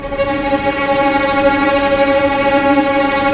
Index of /90_sSampleCDs/Roland LCDP08 Symphony Orchestra/STR_Vcs Bow FX/STR_Vcs Trem p